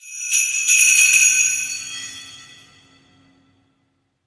jingle_bell_01.ogg